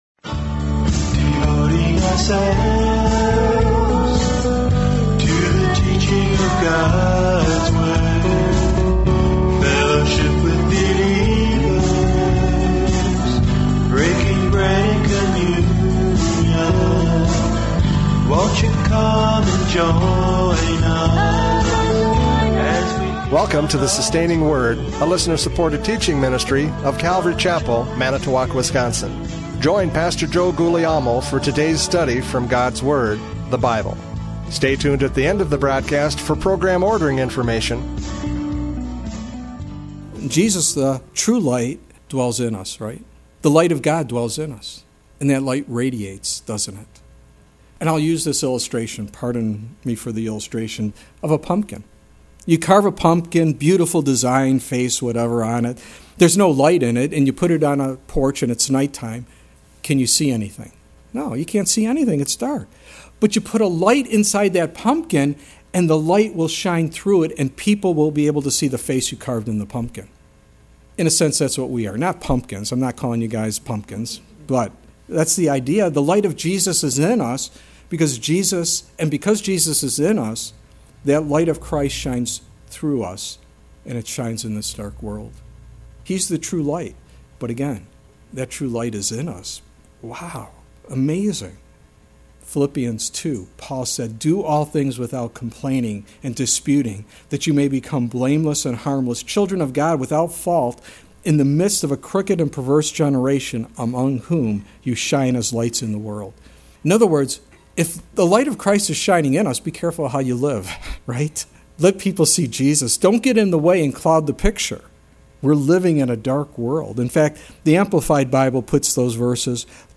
John 1:6-13 Service Type: Radio Programs « John 1:6-13 The Witness and Response!